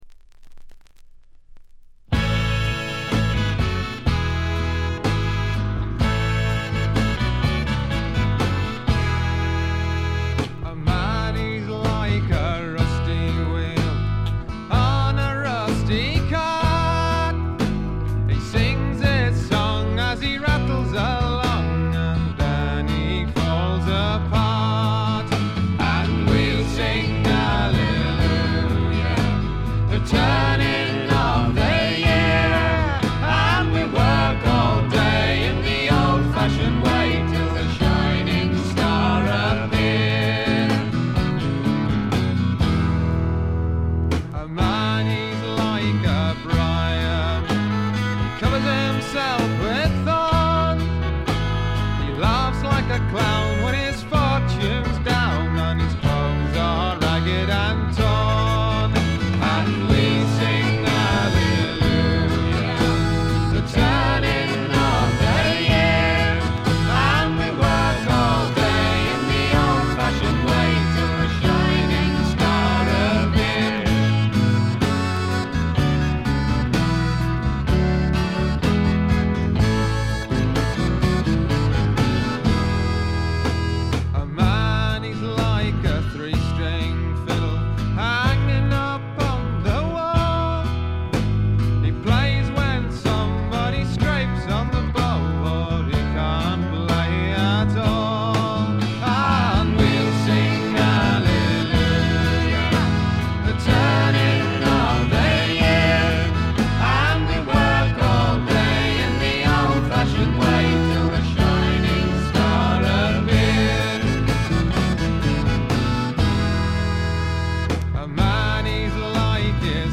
部分試聴ですが、ほとんどノイズ感無し。
英国のフォークロックはこう来なくっちゃというお手本のようなもの。
試聴曲は現品からの取り込み音源です。